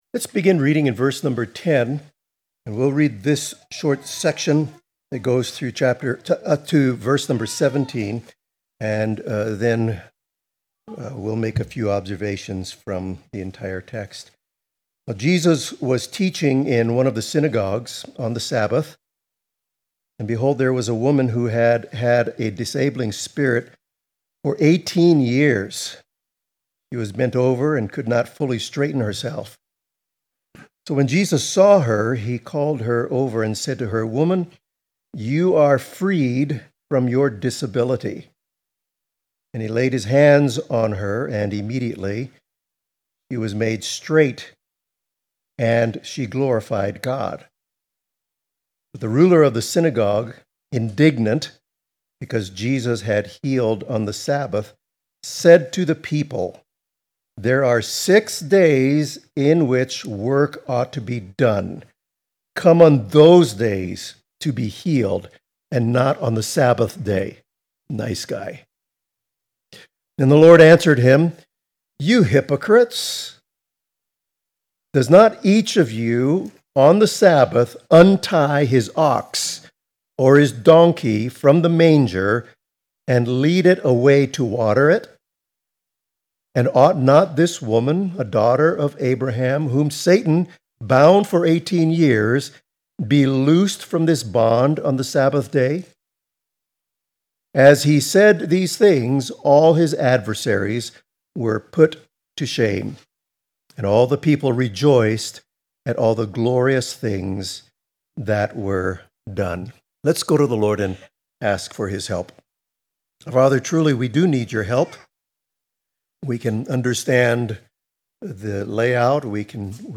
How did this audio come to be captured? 10-17 Service Type: Morning Service Union with Christ is God’s Sabbath for His people.